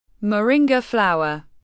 Moringa flower /məˈrɪŋɡə/